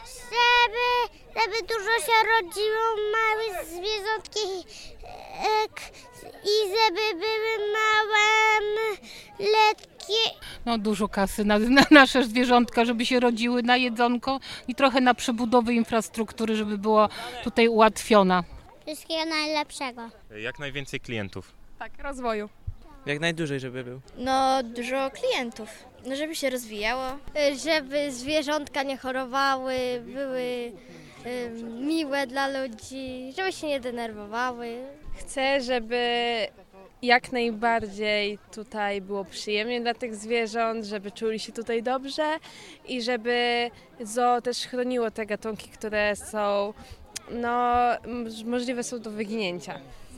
Czego życzą wrocławskiemu zoo odwiedzający?